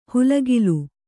♪ hulagilu